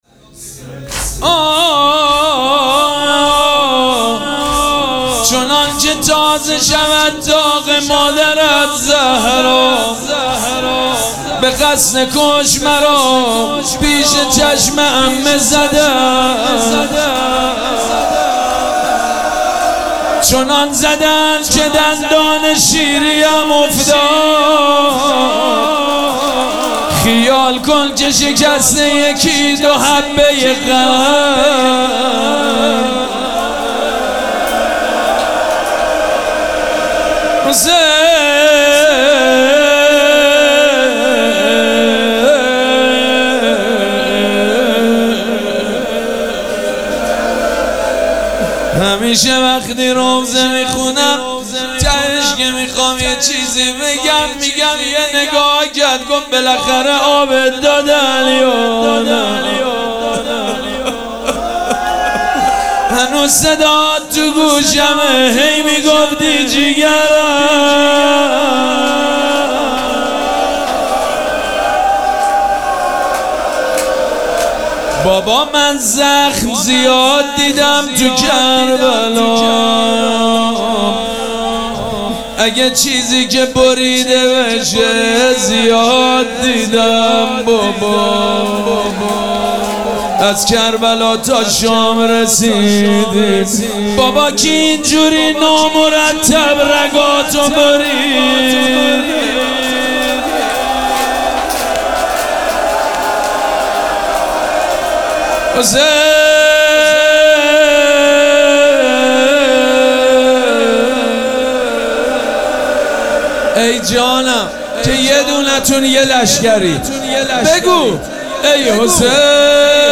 مراسم عزاداری شب شهادت حضرت رقیه سلام الله علیها
روضه
حاج سید مجید بنی فاطمه